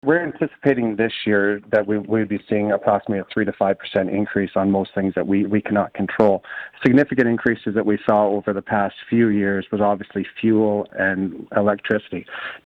We spoke with the Town of Deseronto Mayor Dan Johnston.